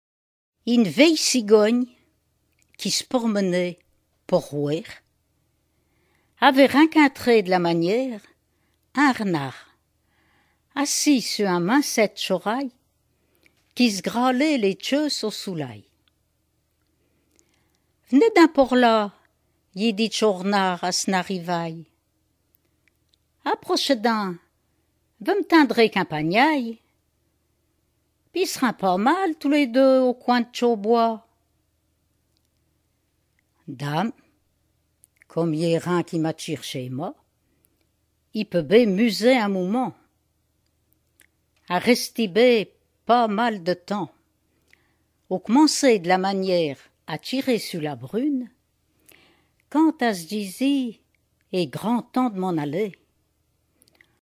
Genre fable
Catégorie Récit